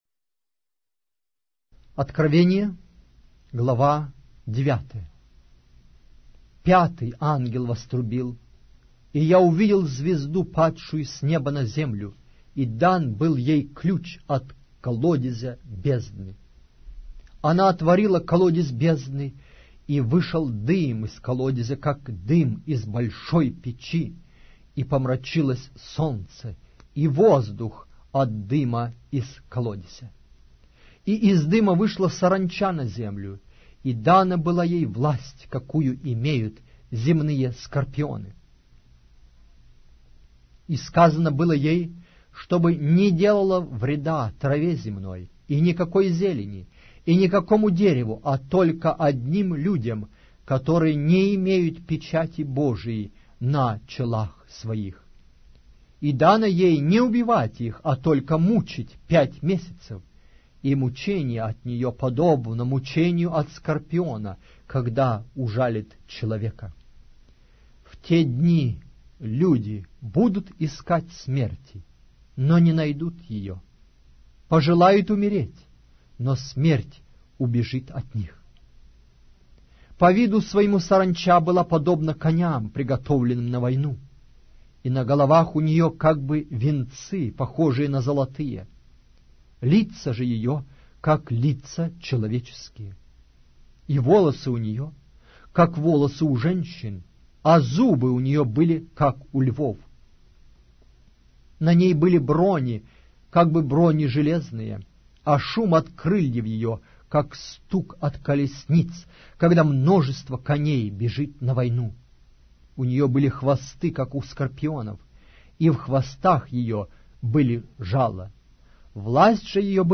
Аудиокнига: Откровение. Иоанна Богослова